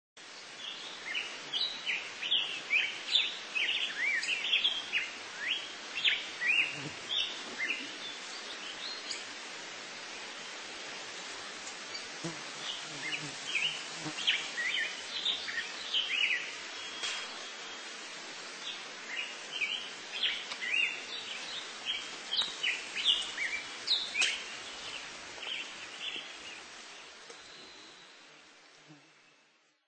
Black-headed Grosbeak
Bird Sound
Song a series of rapidly ascending and descending notes separated by brief pauses. Call note a sharp "chink."
Black-headedGrosbeak.mp3